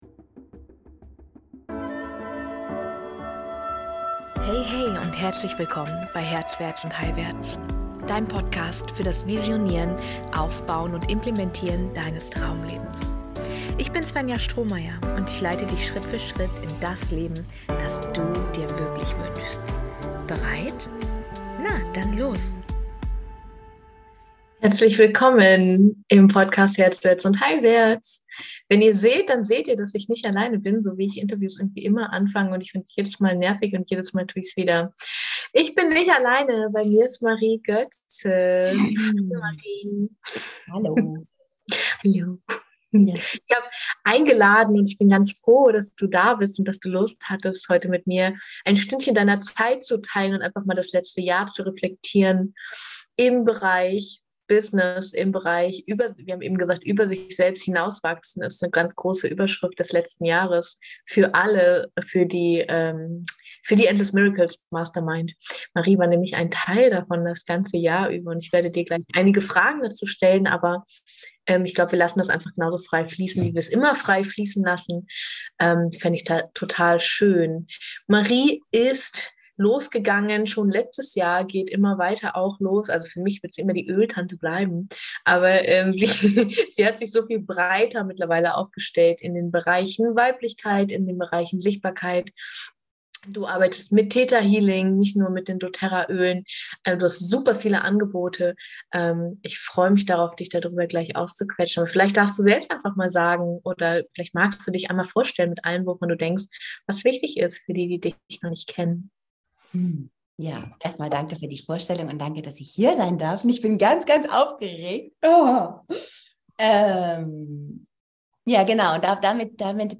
Zur Schöpferin im Business werden - Selbstwirksamkeit verkörpern - Gespräch